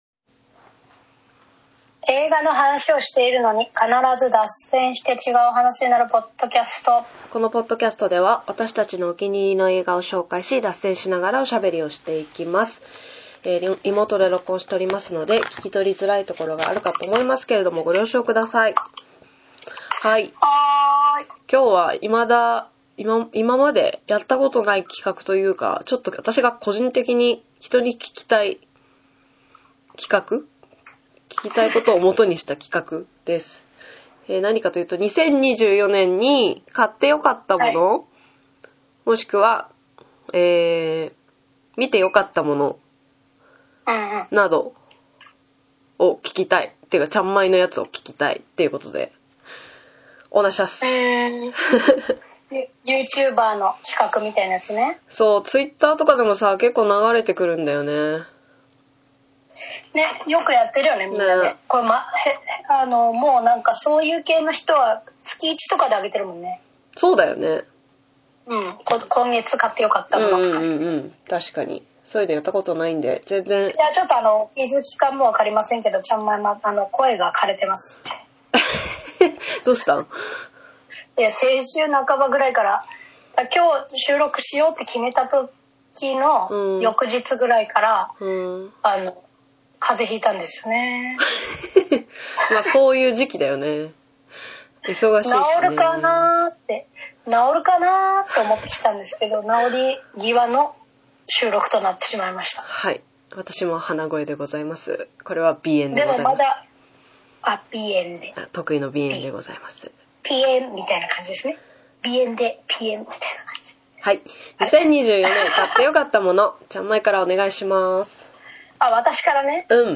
(現在はリモート収録中)毎週月曜日に更新しています。